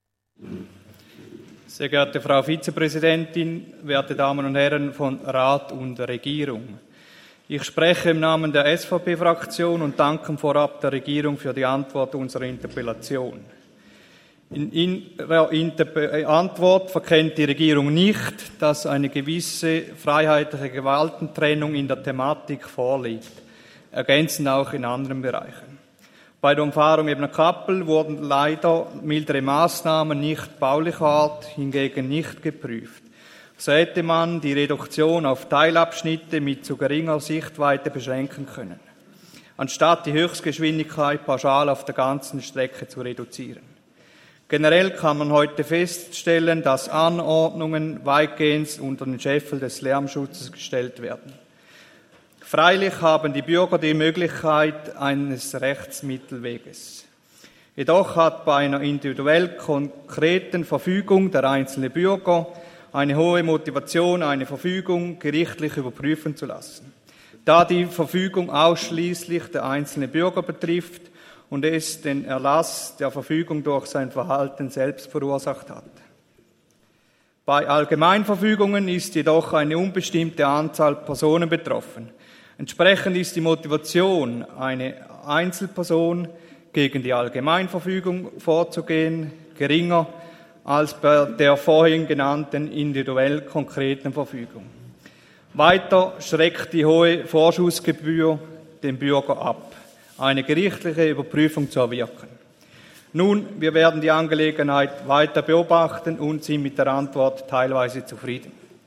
19.9.2023Wortmeldung
Session des Kantonsrates vom 18. bis 20. September 2023, Herbstsession